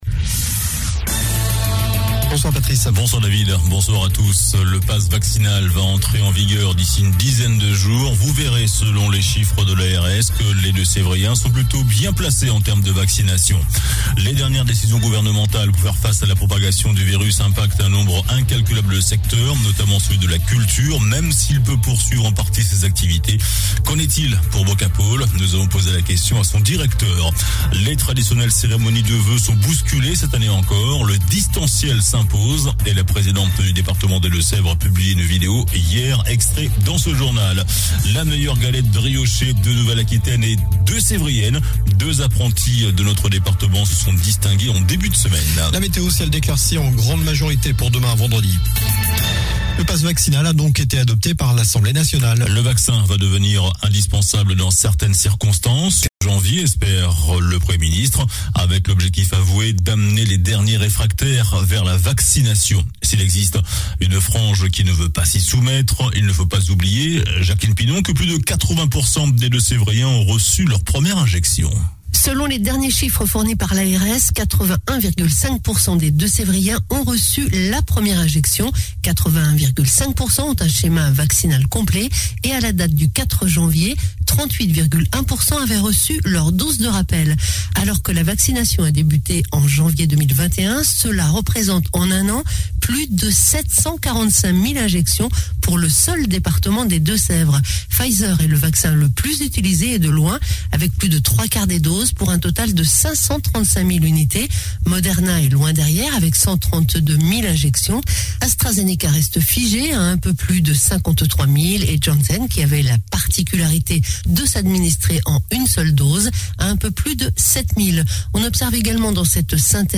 JOURNAL DU LUNDI 06 DECEMBRE ( SOIR )